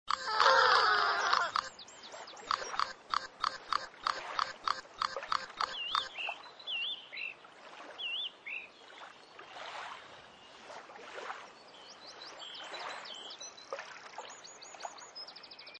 Schwarzstorch
Der Schwarzstorch klappert mit seinem kräftigen großen roten Schnabel und gibt laute und leise gänseartige ,,Gra-Gra"-Rufe von sich von. Der Schwarzstorch zählt zu den Stelzvögeln.
schwarzstorch.mp3